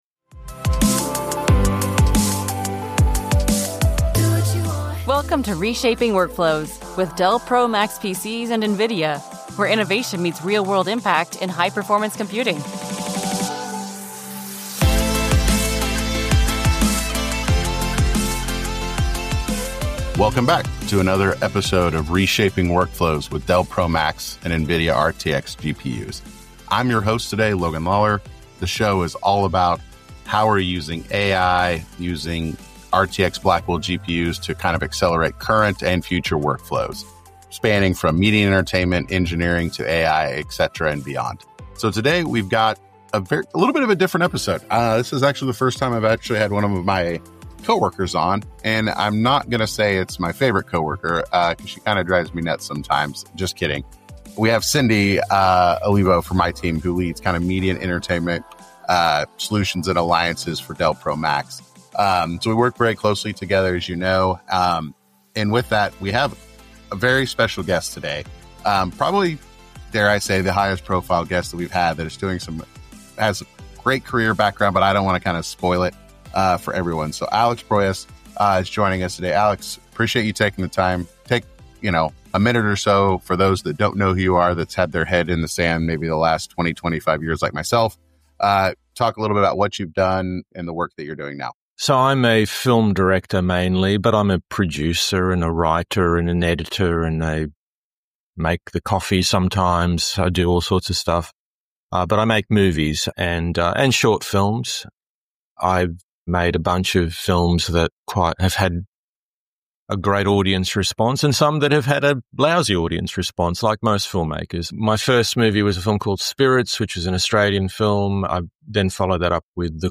Legendary filmmaker Alex Proyas joins this episode of Reshaping Workflows with Dell Pro Max and Nvidia RTX GPUs to reflect on his groundbreaking career and share how AI is reshaping the future of cinema.